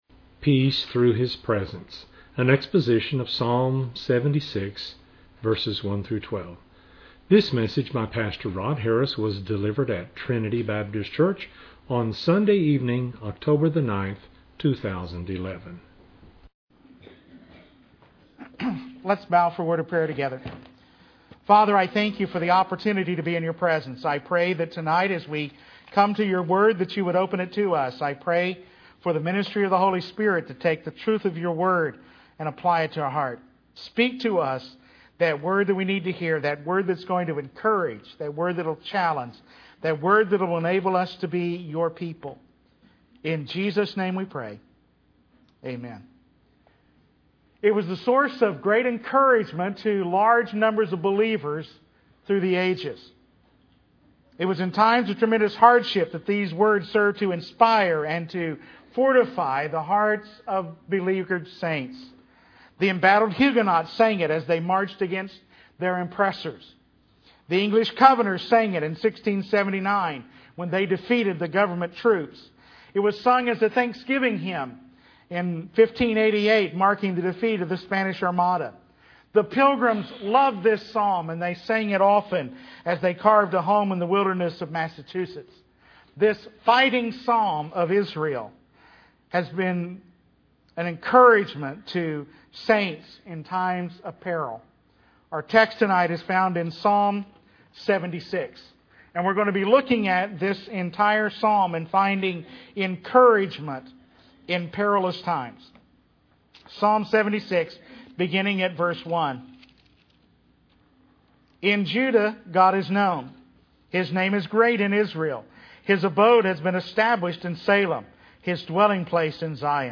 delivered at Trinity Baptist Church on Sunday evening